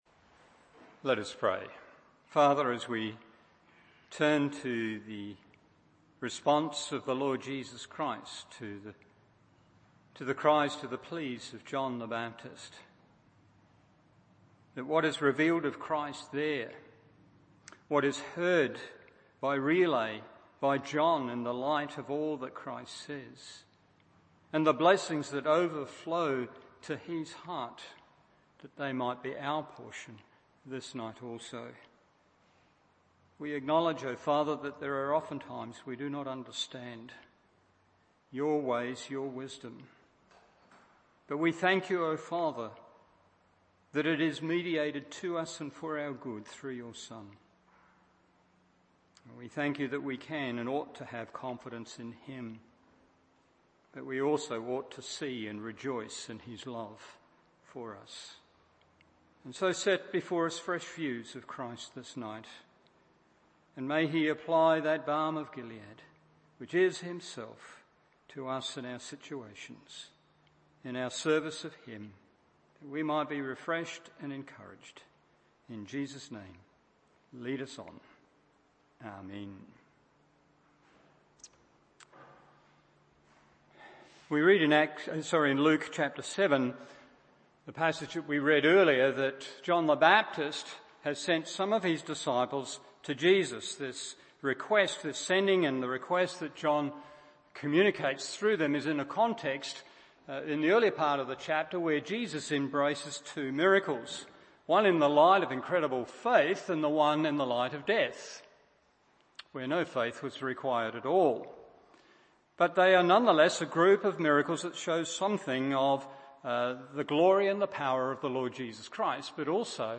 Evening Service Luke 7:24-30 1. Jesus Openly Honours His Servants 2. What Jesus Honours In His Servants 3. Regardless of the Responses of Others…